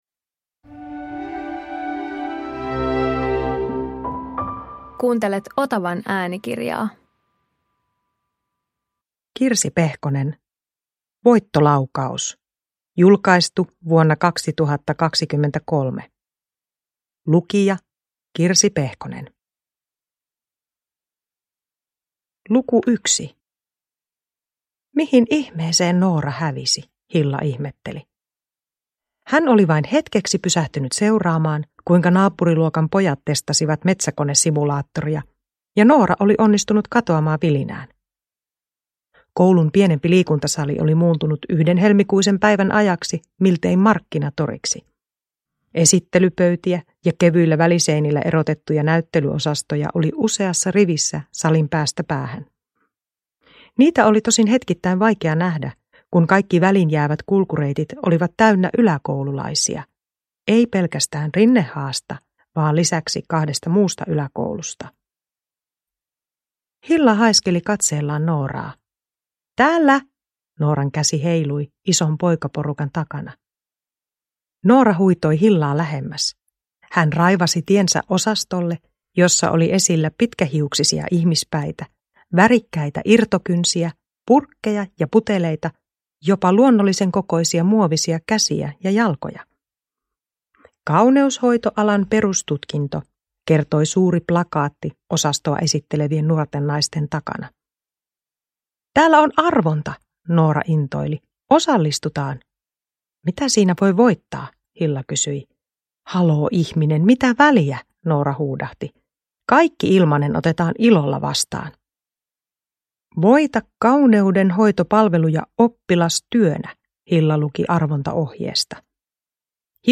Voittolaukaus (ljudbok) av Kirsi Pehkonen